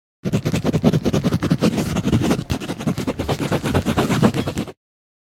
writing.ogg.mp3